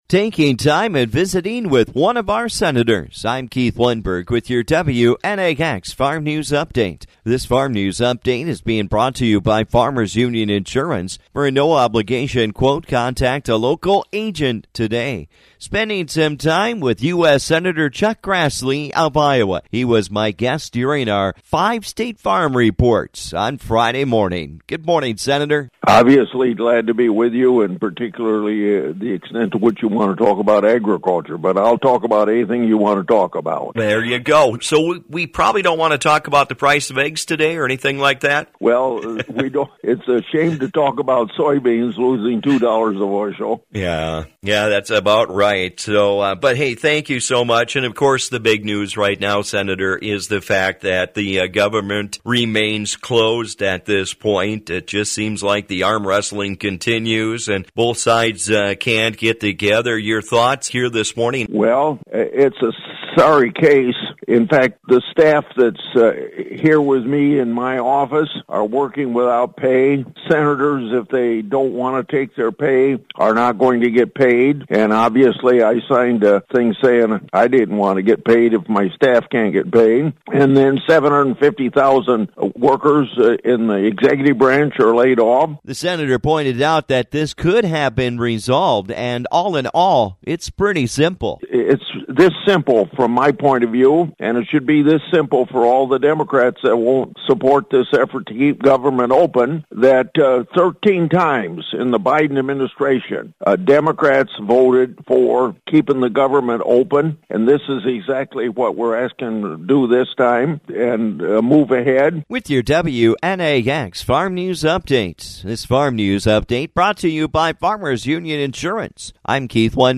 I sat down and talked with U.S. Senator Chuck Grassley of Iowa during our 5-State Farm Report.